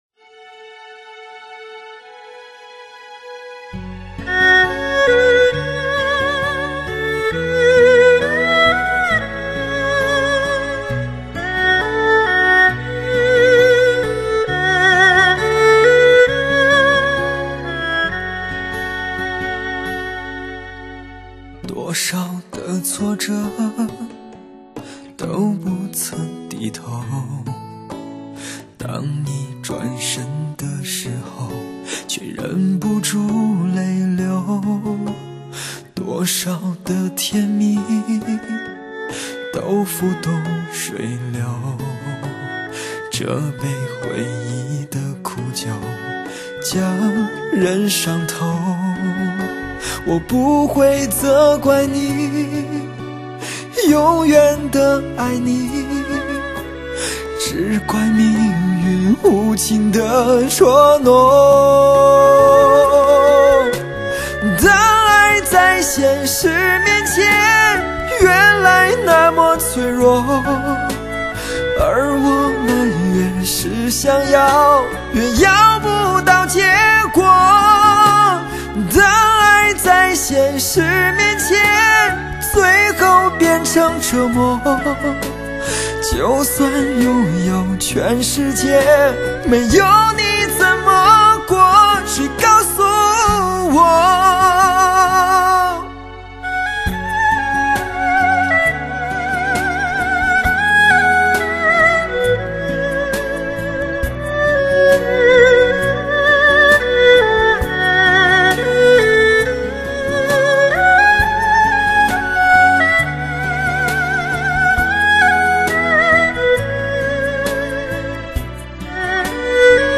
从百张音质爆棚中再精选出最好最靓音质的曲目出来， 定位依然精绝，层次依然丰富与低频变幻依然多姿彩，再次拍案称奇！